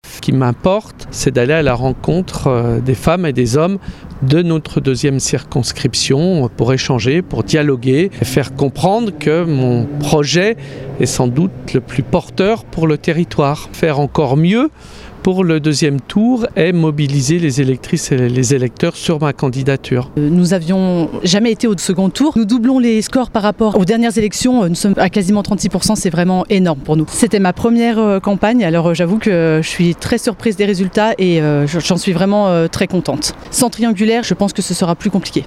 Ecoutez les deux candidats :